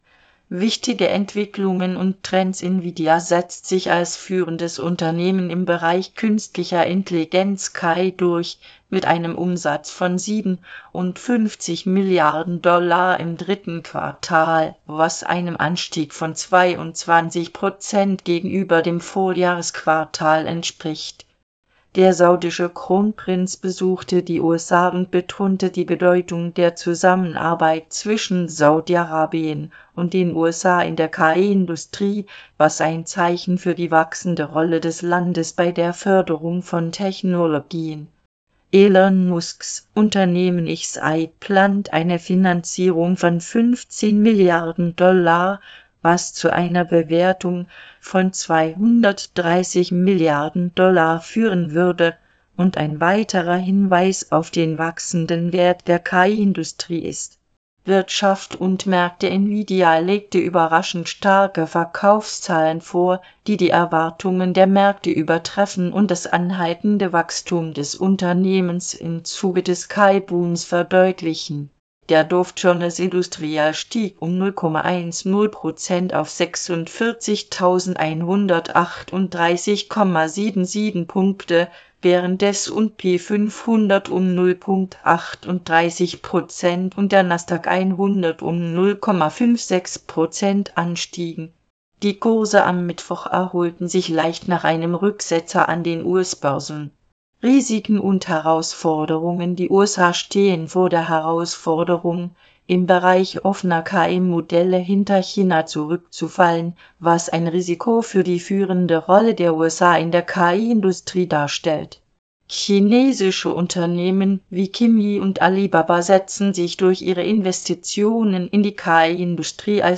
Vorlesen (MP3)